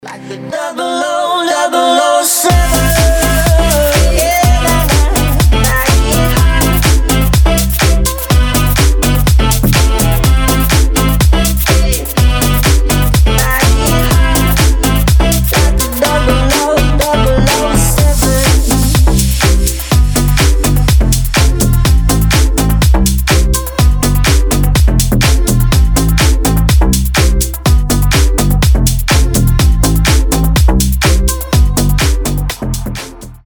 ритмичные
deep house
EDM
Club House